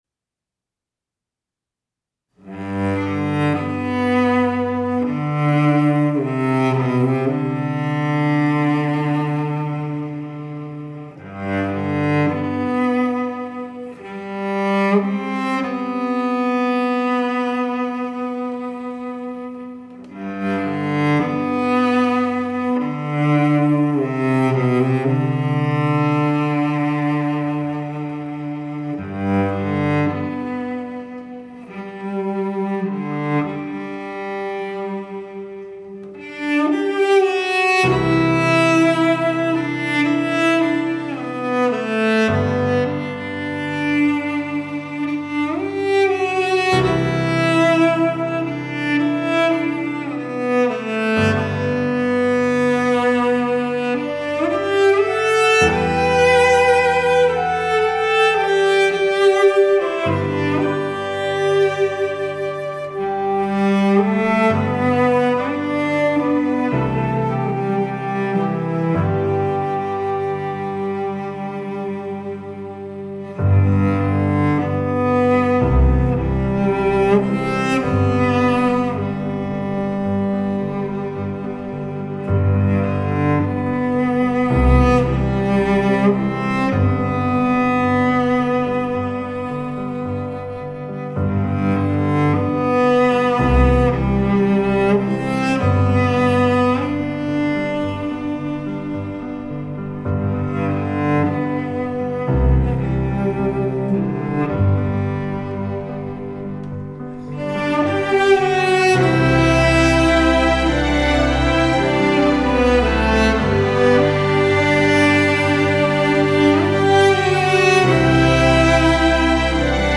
cello
I recorded her part in Logic Pro and then orchestrated around that with virtual instruments to give the cello line some movement and lift.